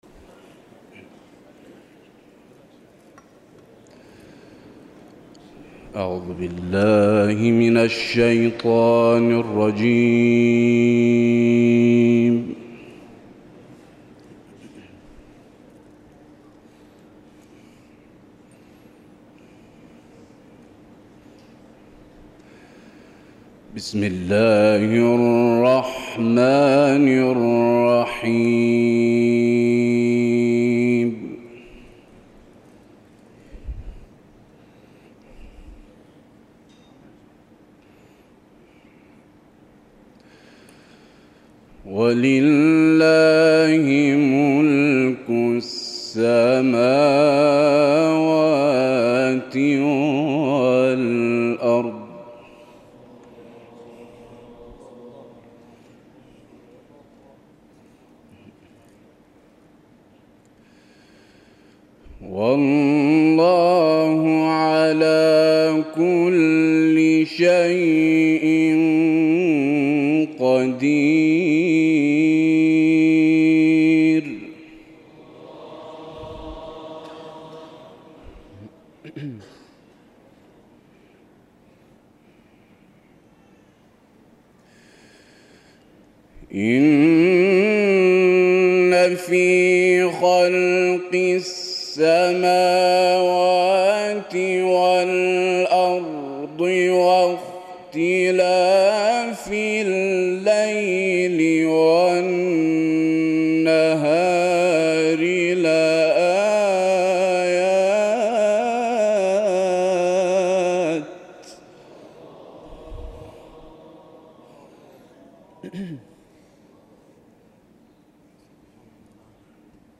تلاوت یک قاری در محضر رهبر انقلاب و تحسین حضار+صوت
قاری